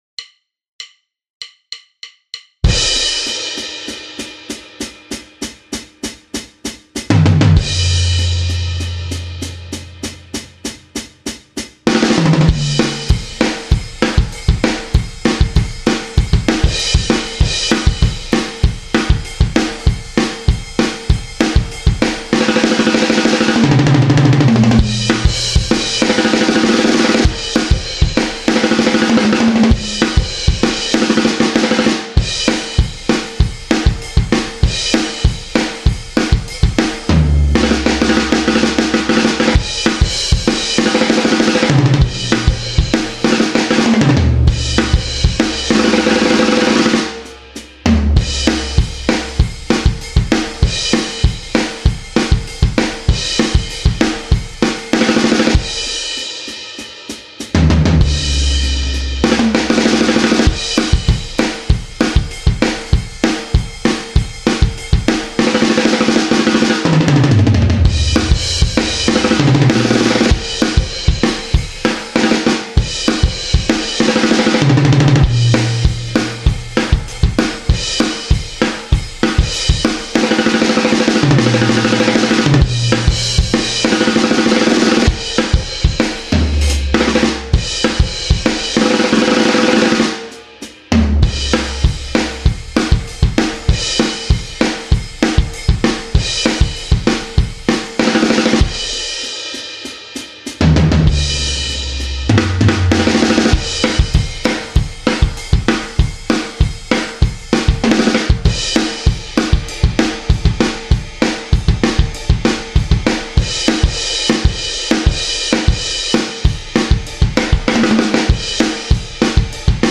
Rock Kit